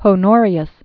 (hō-nôrē-əs), Flavius AD 384?-423.